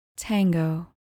Pronounced: TANG-go